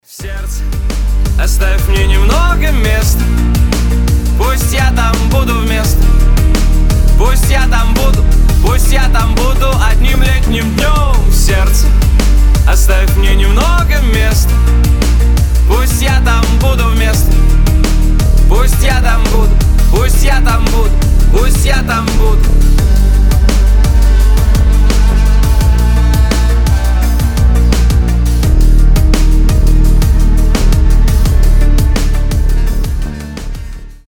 поп , романтические